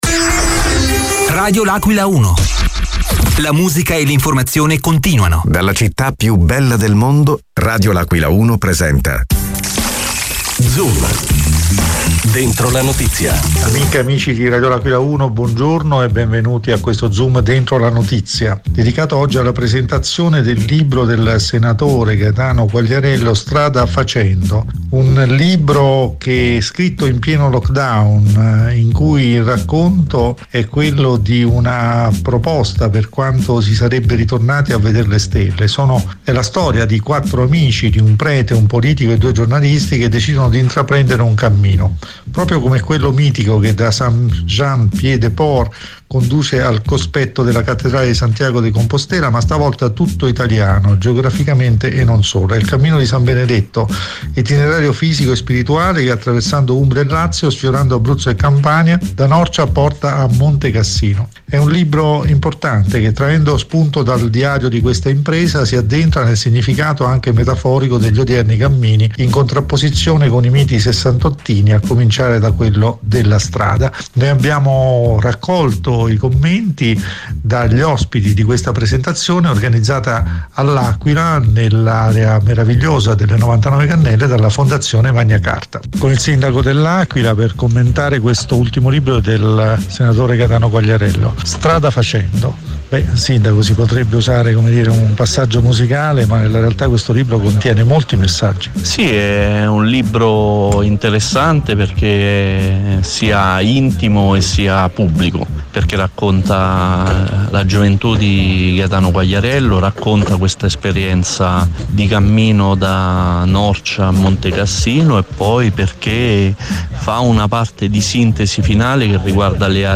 Zoom dentro la notizia del 12.07.2021: presentazione di “Strada facendo” di Gaetano Quagliariello
All’incontro ha partecipato il Sindaco dell’Aquila Pierluigi Biondi.